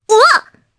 Requina-Vox_Damage_jp_02.wav